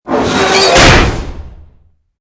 CHQ_VP_collapse.mp3